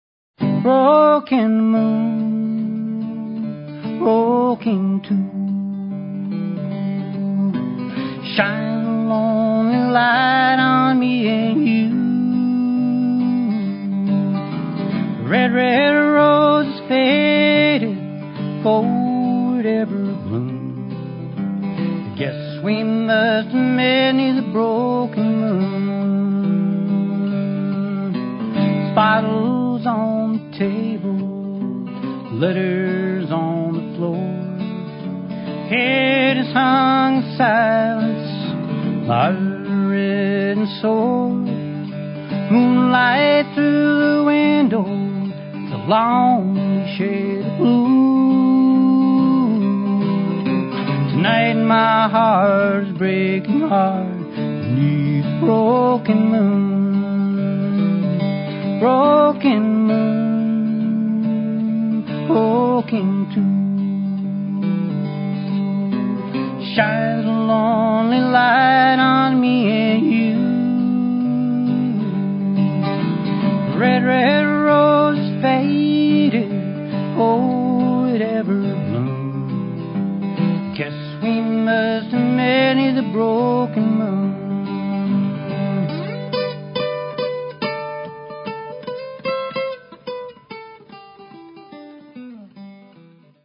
recorded live in front of an audience